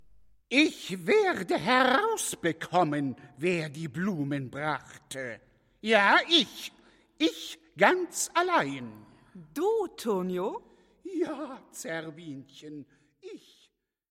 Die vorliegende Einspielung beruht auf der Uraufführung des gesamten Werks bei der Theater&Philharmonie Thüringen und wurde im September 2008 im Konzertsaal der Bühnen der Stadt Gera aufgenommen.